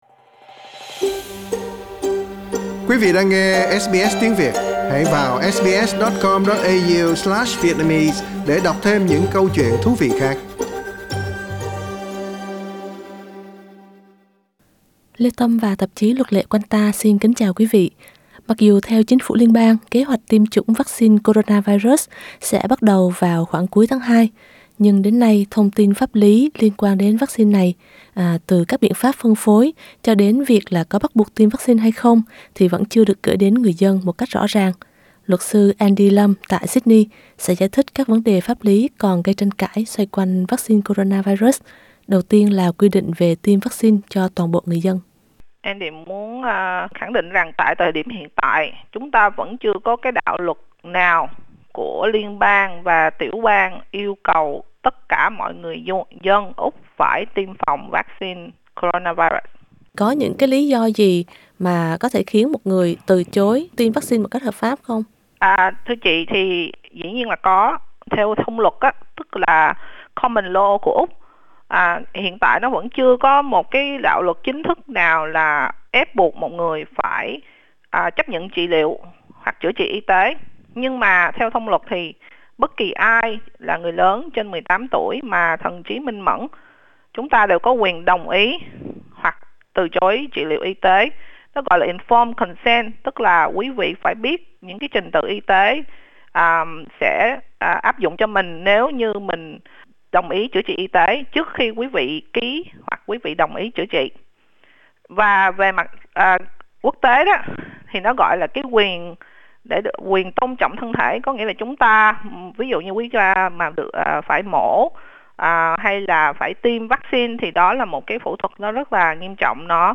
Mời quý vị bấm vào biểu tượng radio để nghe toàn bộ bài phỏng vấn